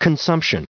added pronounciation and merriam webster audio
168_consumption.ogg